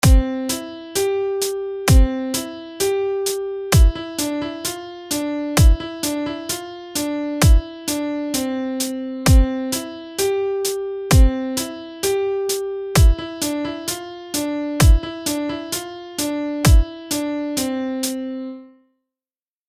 Ponechte bouchat buben v každém osmém políčku (tedy v 1., 9., 17., 25. atd. políčku skladby) a přidejte k němu bicí nástroj "Hi-Hat" nebo nějaký jiný slabší, méně výrazný bicí zvuk na každé druhé políčko (takže jich bude čtyřikrát víc než těch původních bubnů).
Pokud jste někdy slyšeli metronom, jistě uznáte, že zní dost podobně jako tyto bicí.
Každý takt má čtyři doby.